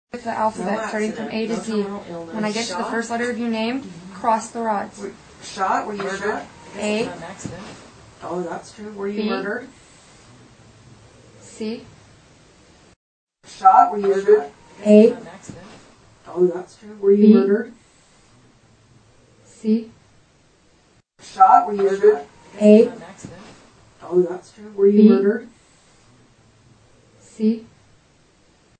Scream - Recorded during the dowsing rod section. It is very quick, and happens right after the lady askes if the ghost was shot or murdered. Original x1 cleaned and amplified section x2.
tooelefarscream.mp3